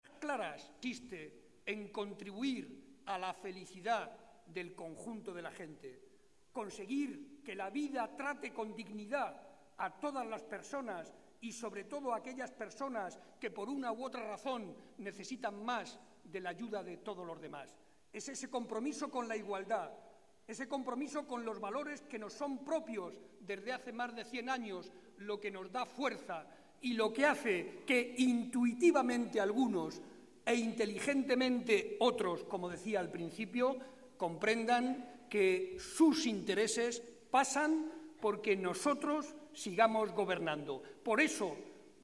Barreda que hacía estas declaraciones en el marco de la cena que con motivo de la Navidad celebra tradicionalmente el PSOE de Guadalajara, explicó además que el Grupo Parlamentario Socialista presentará una enmienda en la que se defenderán todos los planteamientos que reivindica nuestra Comunidad Autónoma, entre ellos el agua.
Audio Barreda cena navidad psoe gu 2